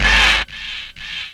68_23_stabhit-A.wav